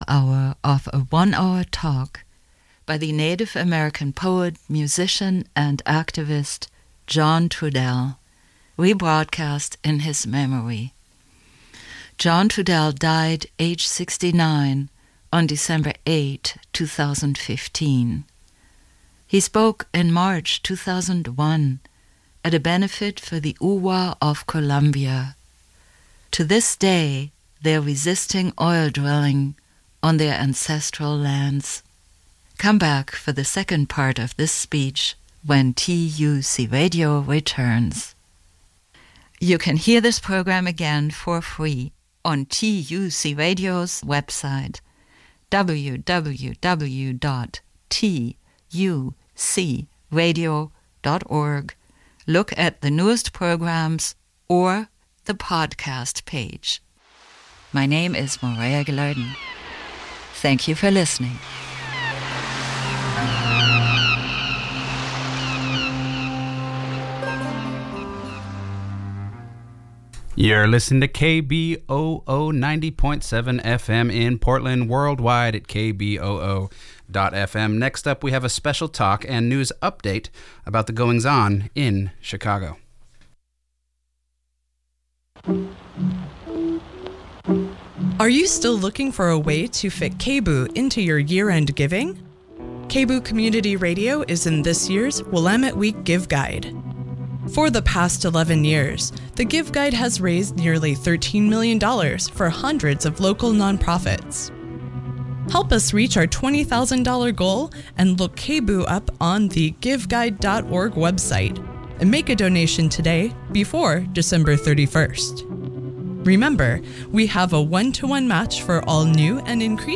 Despite what he knows, Tim Flannery explains new "natural" based tech that may prevent climate catastrophe. Tim Flannery speech recorded at the Town Hall in Seattle November 12, 2015 by ...